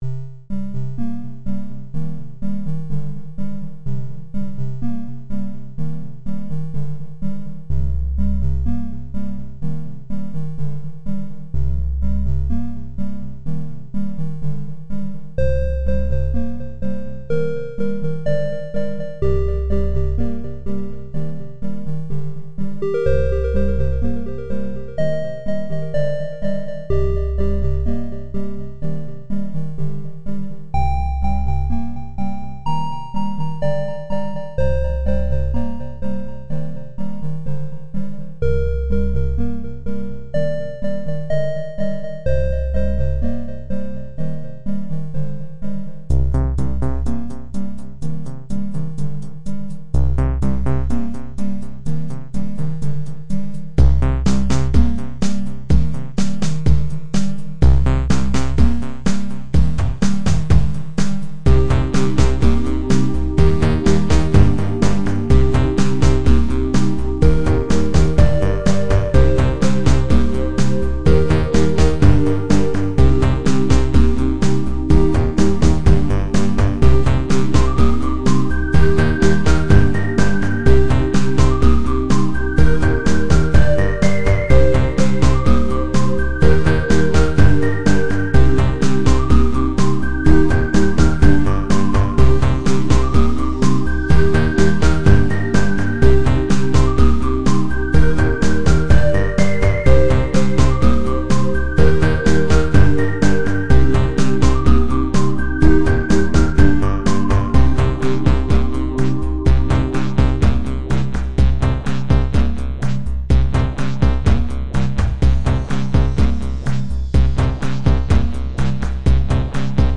Relaxing nostalgic-sounding chippy track.
• Chip music